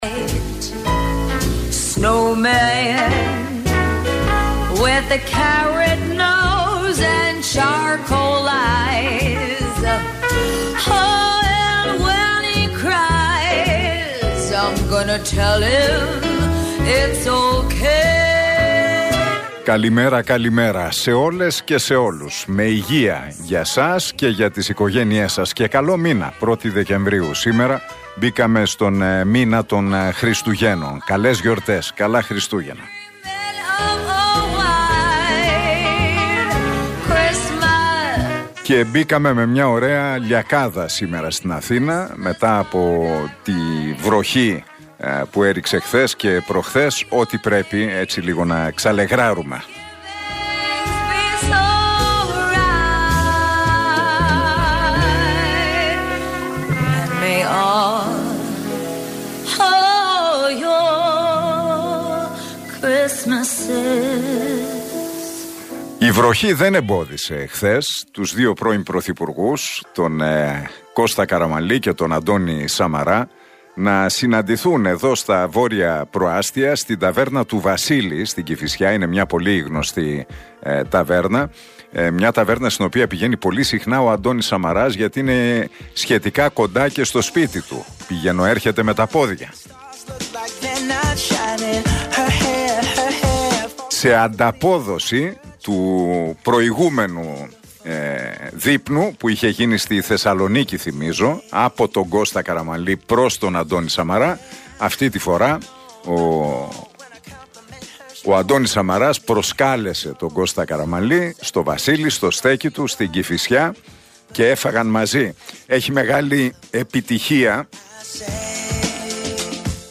Είναι μια στιγμή με έντονη πολιτική αντιπαράθεση ανάμεσα στην κυβέρνηση και την αντιπολίτευση λόγω του θέματος των παρακολουθήσεων», ανέφερε ο Νίκος Χατζηνικολάου στην εκπομπή του στον Realfm 97,8.